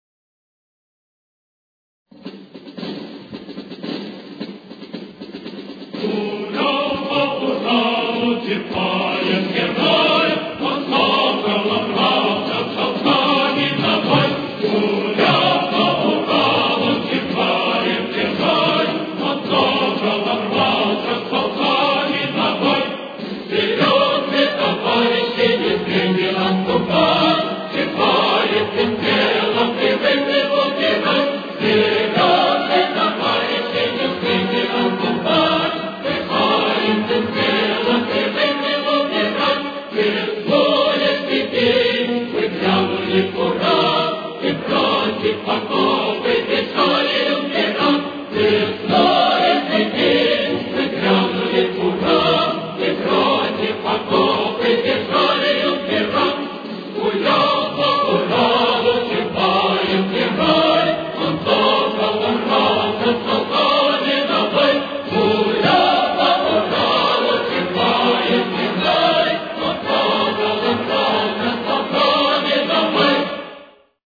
Темп: 115.